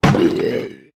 Minecraft Version Minecraft Version snapshot Latest Release | Latest Snapshot snapshot / assets / minecraft / sounds / entity / shulker / death2.ogg Compare With Compare With Latest Release | Latest Snapshot